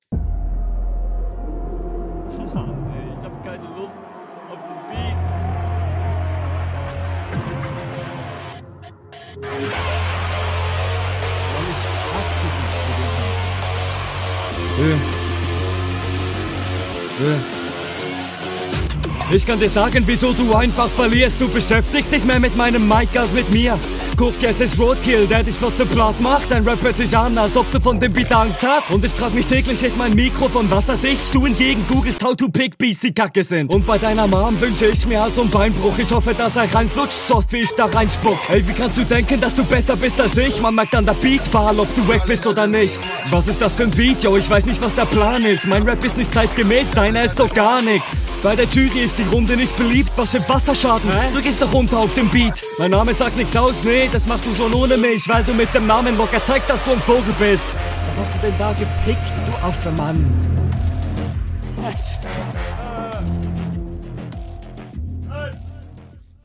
Man merkt hier, dass die mische hier leider bisschen reinsuckt und man deinen stimmeinsatz nicht …